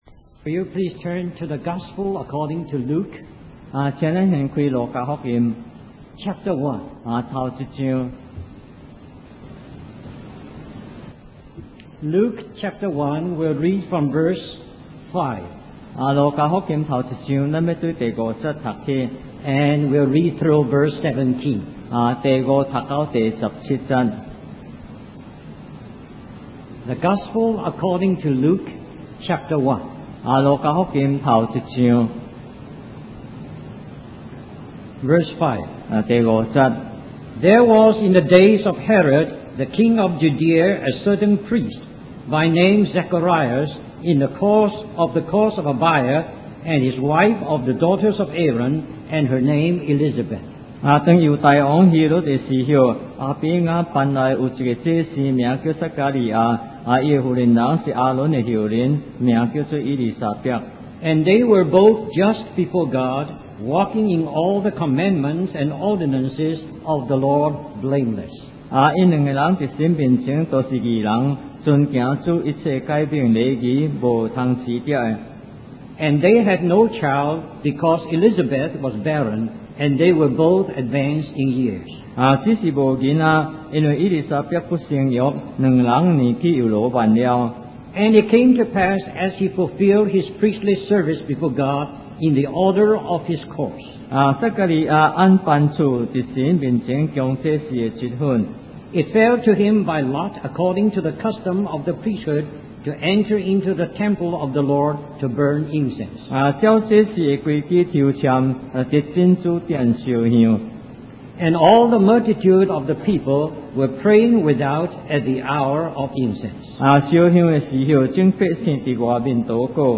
In this sermon, the preacher discusses the role of John the Baptist as an epic-making vessel of God. The preacher emphasizes that God's purpose never changes, but his ways and tactics may change over time.